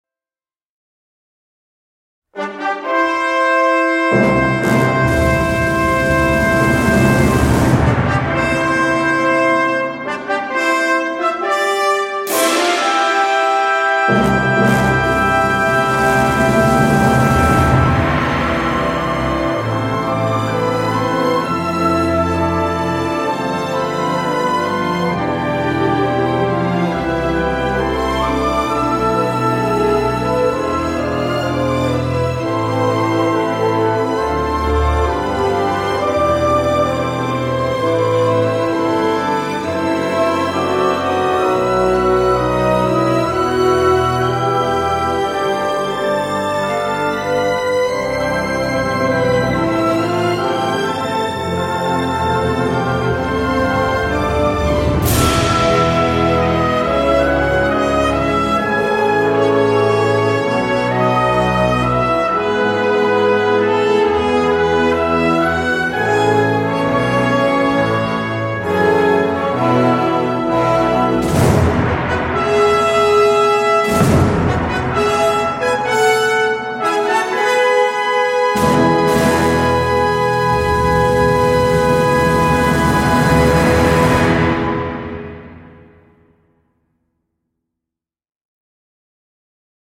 Pas assez enlevés à mon goût.
Une compilation live sertie de nombreuses pépites